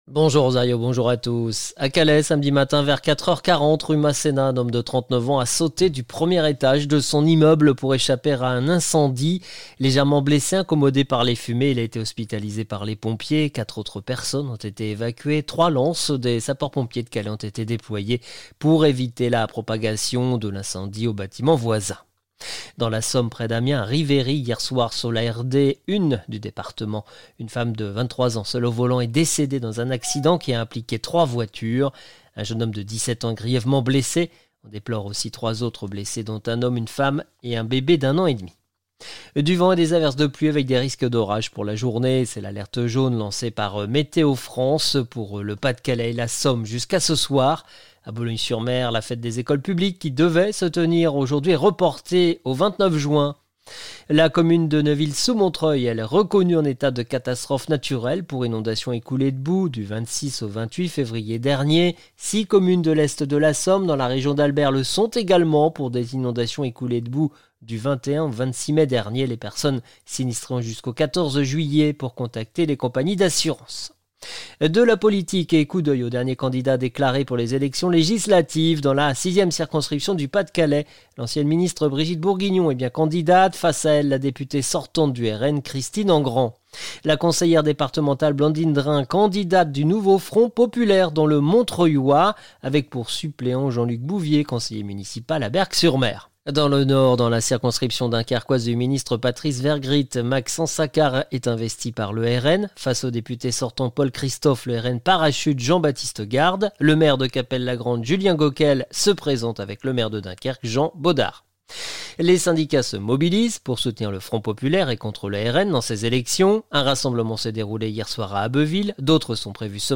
LE JOURNAL CÔTE D'OPALE ET CÔTE PICARDE DU samedi 15 juin 2024